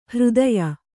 ♪ hřdaya